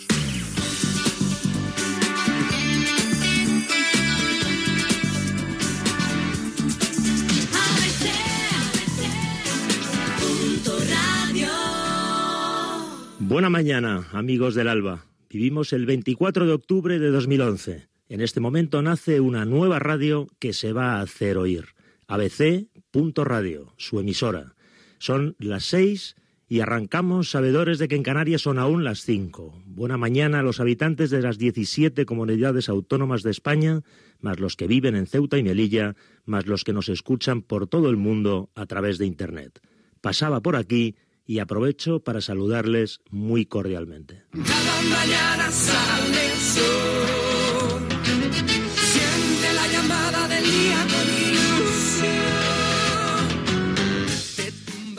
Indicatiu de la ràdio i inici del primer programa
Info-entreteniment
FM